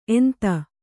♪ enta